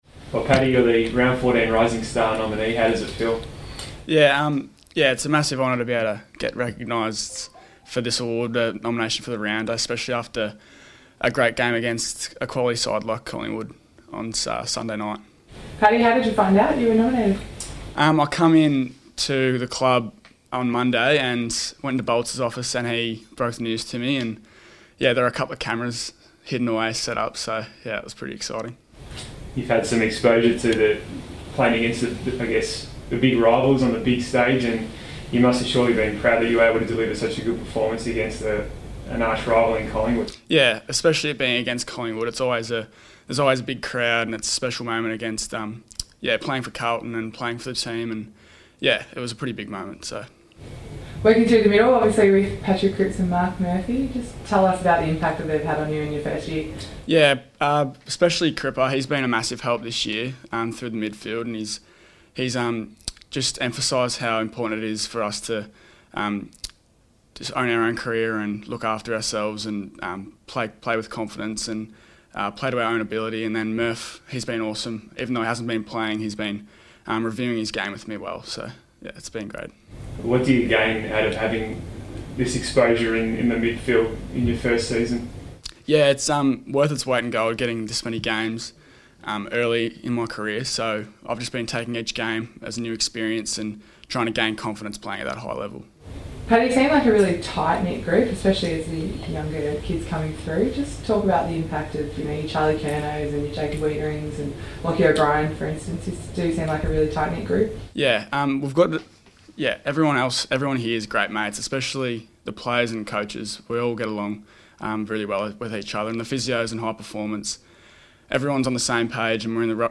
Paddy Dow press conference | June 26
Carlton midfielder Paddy Dow speaks to the media following his Round 14 NAB AFL Rising Star nomination.